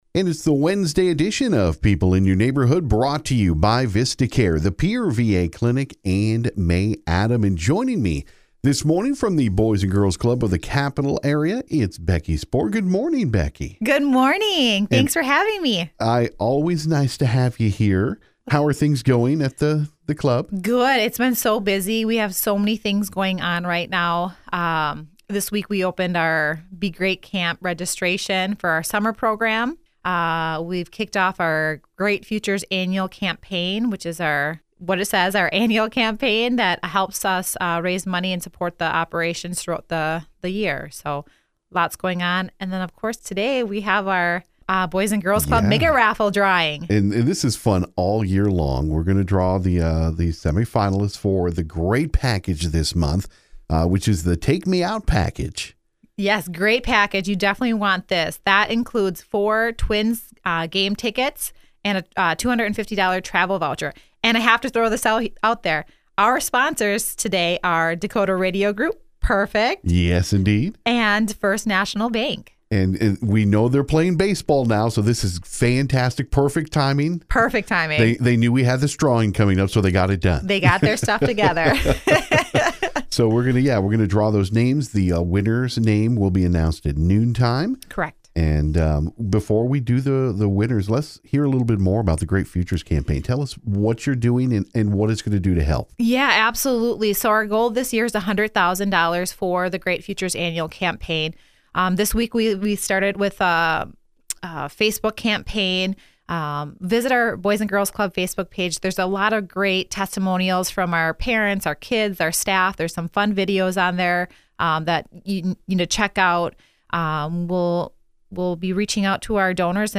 visited KGFX